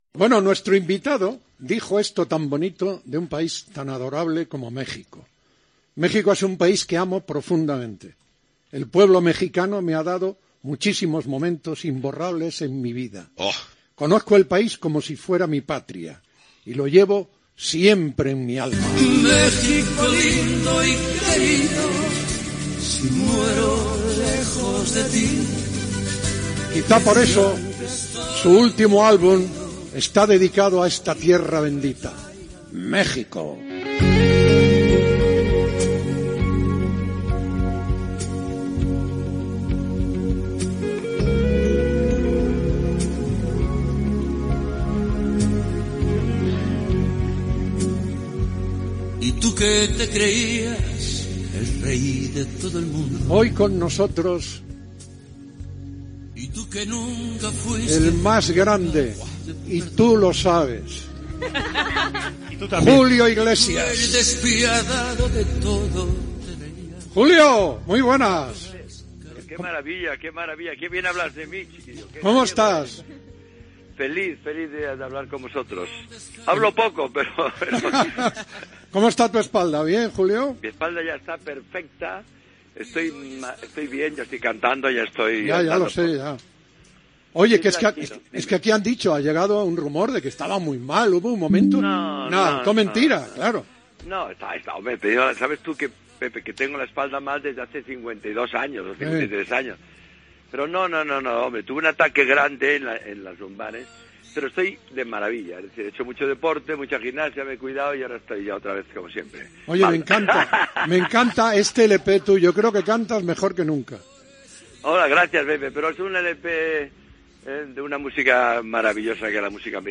Fragment d'una entrevista al cantant Julio Iglesias, amb motiu de la publicació del seu disc "México". Amb un gol en el partit entre l'Alcorcón i el Llagostera Gènere radiofònic Esportiu